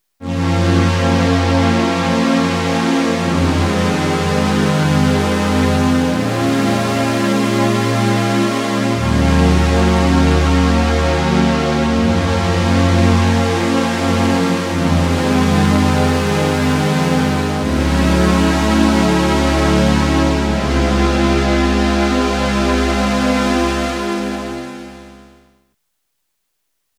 - added "test_supersaw" demosong (a simple test for the "noise" LFO feature) (
audio demo)